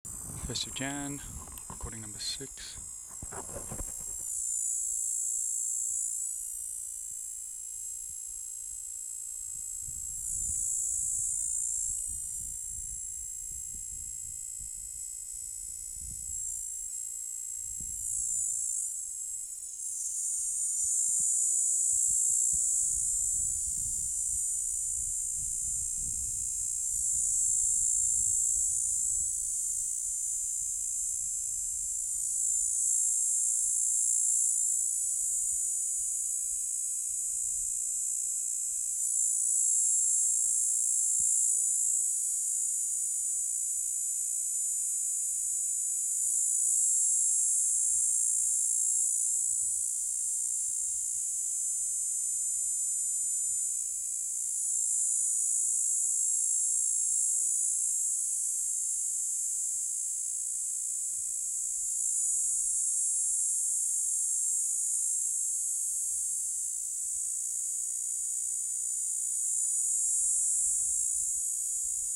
898: Platypleura "sp. 12 cf. hirtipennis"
Africa: South Africa: Western Cape: 2.5km E of Ganskop